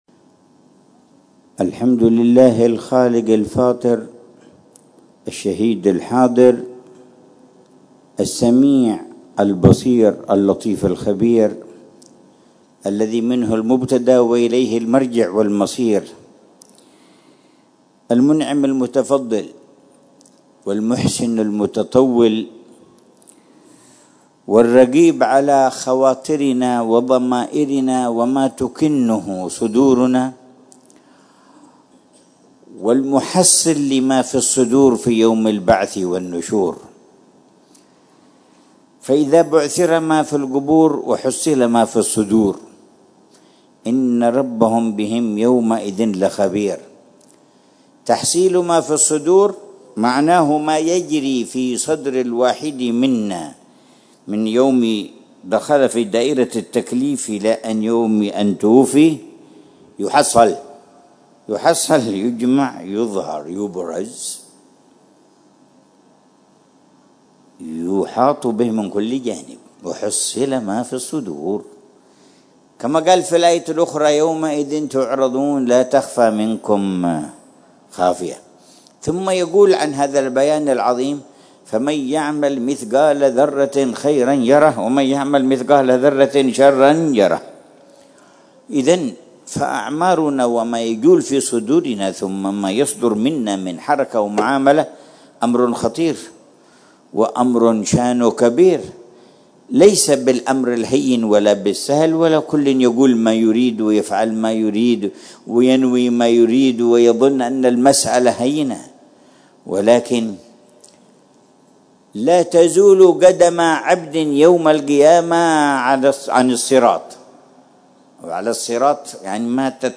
محاضرة العلامة الحبيب عمر بن محمد بن حفيظ في جلسة الجمعة الشهرية الـ61، في الساحة الشرقية لجامع عمر بن الخطاب، باستضافة حارة المطار، بمدينة تريم، ليلة السبت 17 محرم 1447هـ، بعنوان: